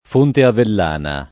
fonte [f1nte] s. f. («sorgente; fontana») — anche s. m. nell’uso ant. e poet.; sempre masch. il fonte battesimale, il sacro fonte — freq. in toponimi: Fonte Avellana [